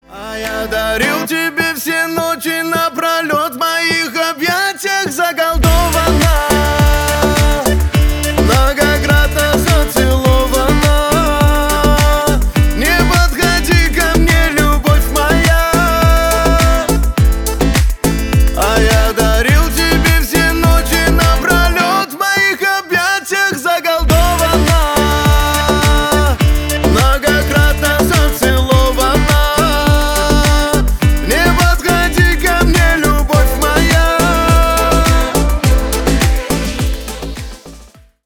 Поп Музыка
кавказские # грустные